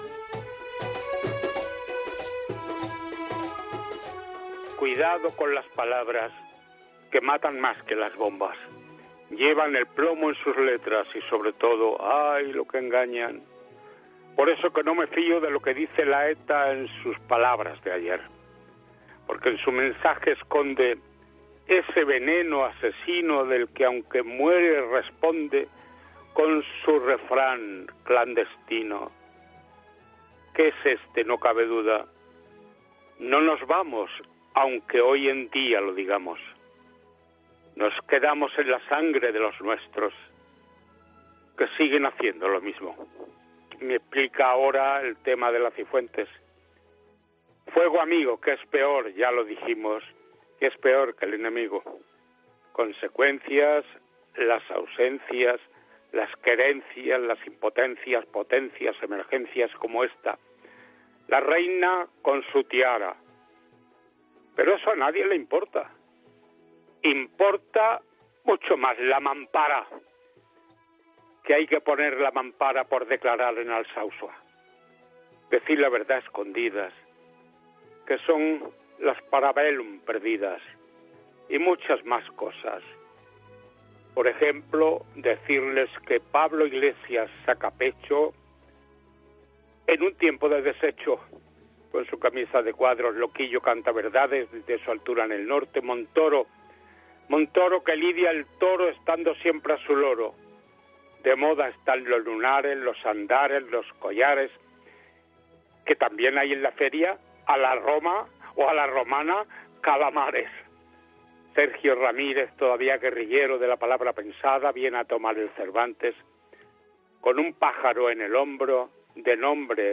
El maestro Tico Medina cierra la puerta, como cada viernes, de 'Herrera en COPE' con su "puñaíto" de ripios que conforman todo un racimo.